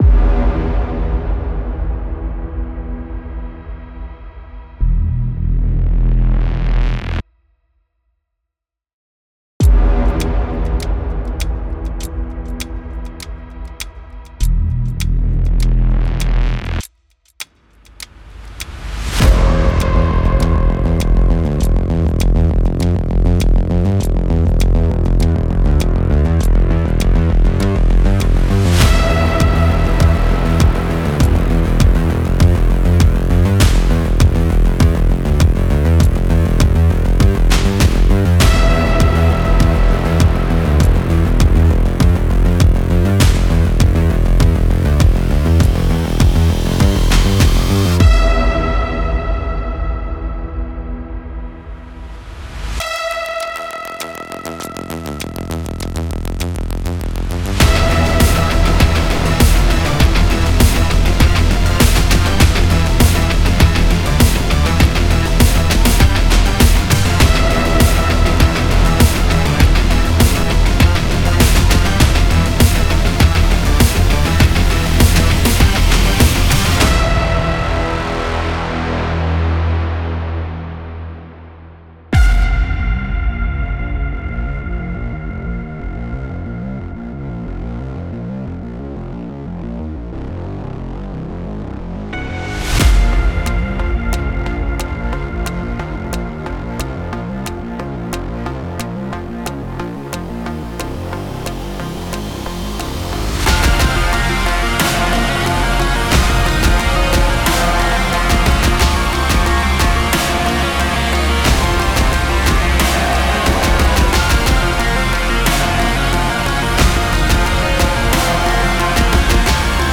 Genre:Sci-Fi
暗く、機械的で、感情の高まりを秘めた世界観です。
デモサウンドはコチラ↓